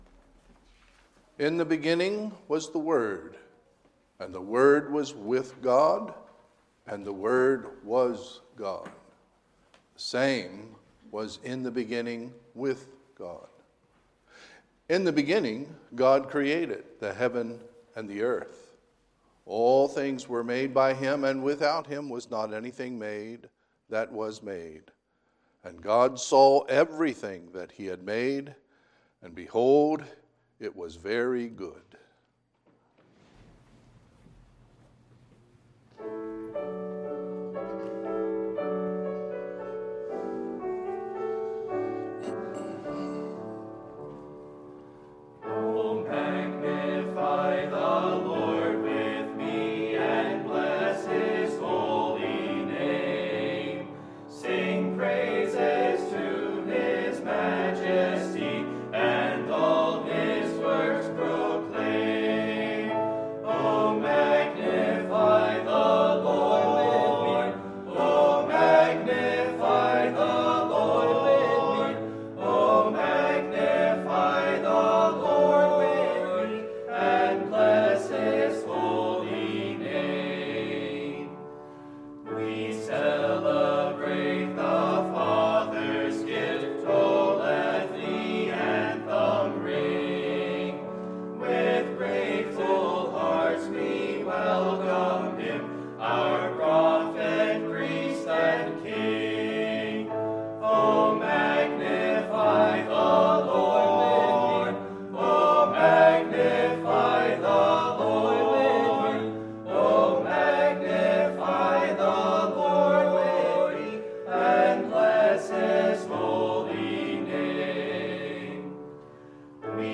Sunday, December 19, 2021 – Sunday AM
North Hills Bible Church Christmas Program